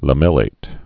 (lə-mĕlāt, lămə-lāt)